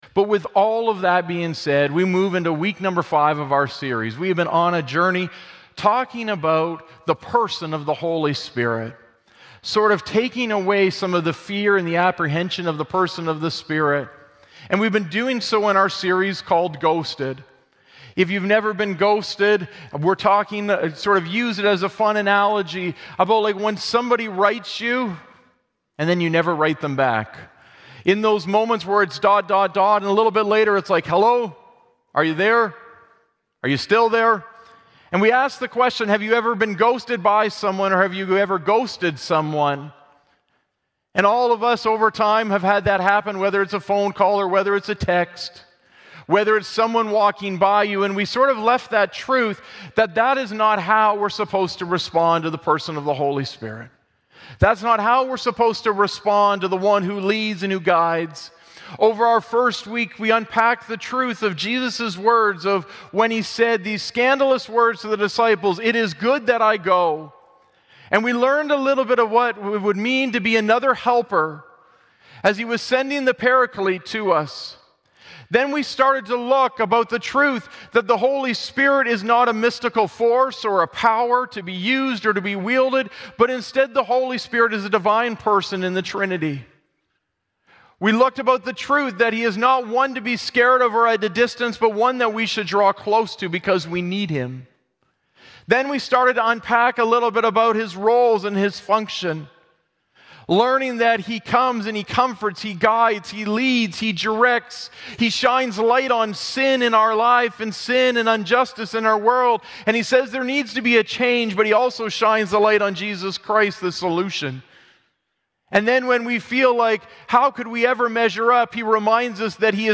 Sermon Podcast